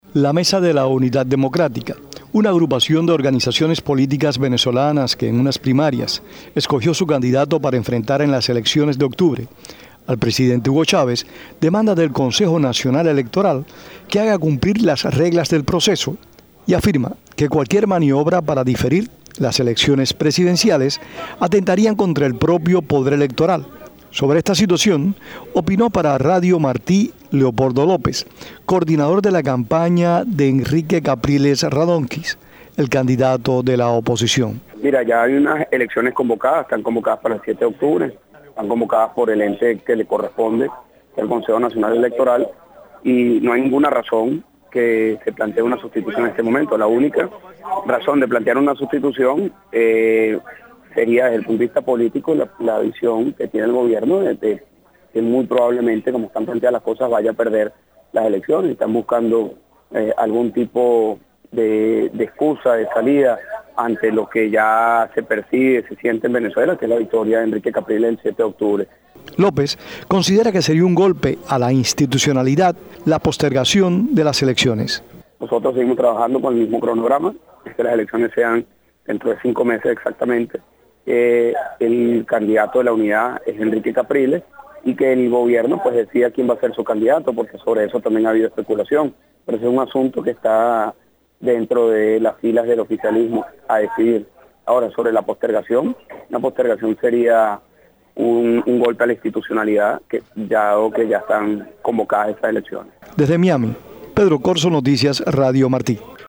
Reportaje de Radio Martí con declaraciones de Leopoldo López